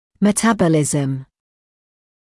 [mə’tæbəlɪzəm][мэ’тэбэлизэм]метаболизм, обмен веществ